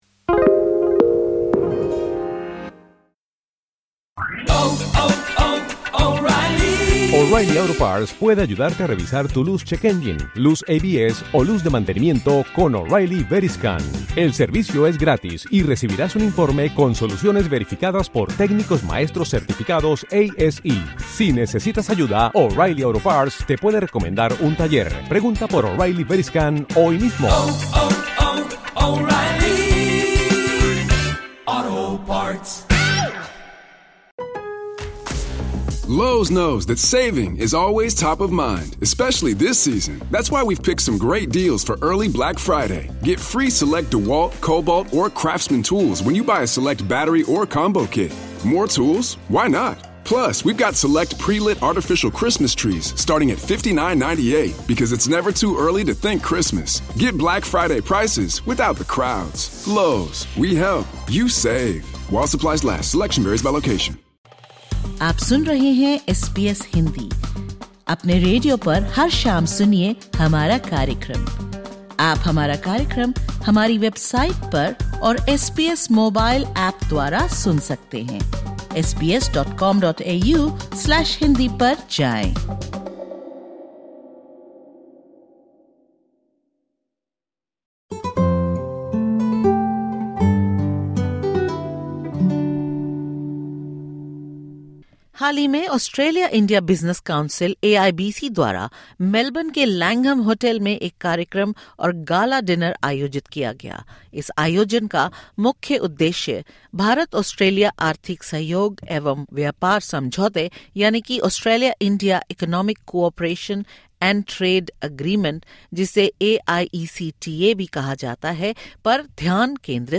In this conversation with SBS Hindi, he discusses how stronger bilateral ties are fuelling economic growth, unlocking new opportunities and positioning Victoria as a hub for global collaboration and progress.